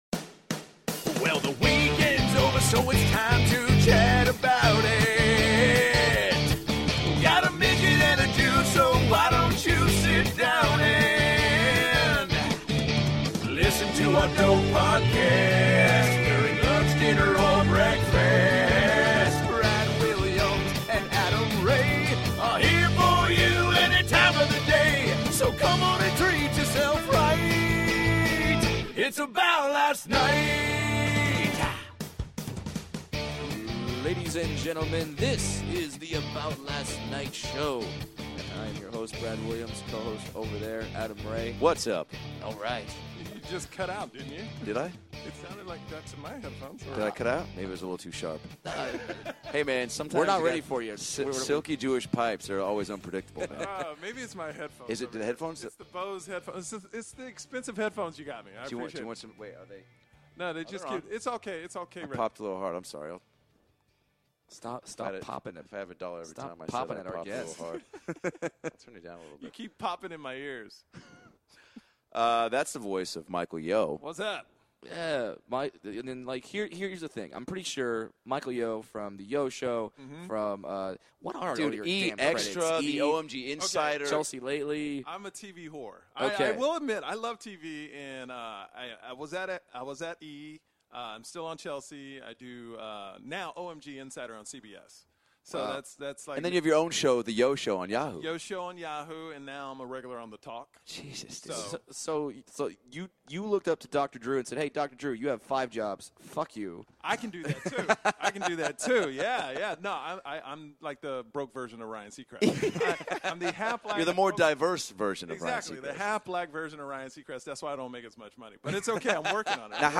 The guys invite multi talented stand up Michael Yo to talk about Jewish Deli's in Houston and waxing your ass.